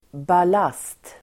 Uttal: [b'a:las:t]